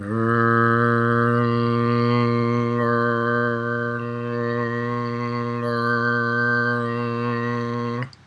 L and R sounds alone
r-l-alternate-short.mp3